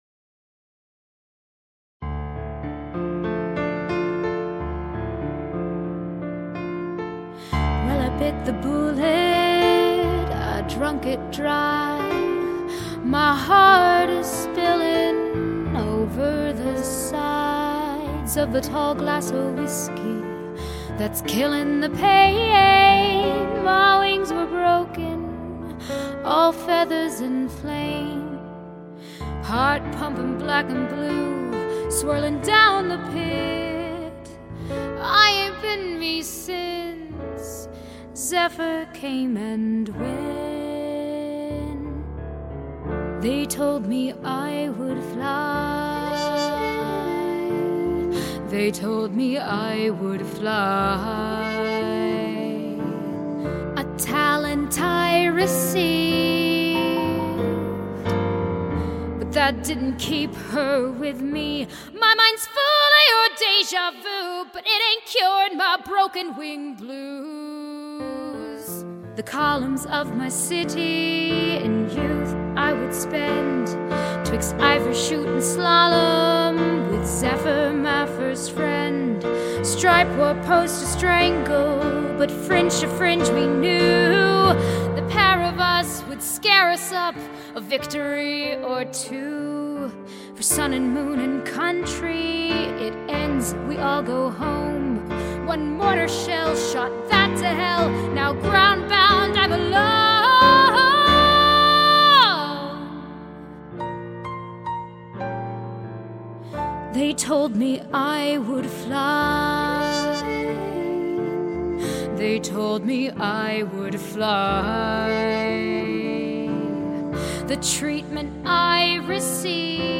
piano recital version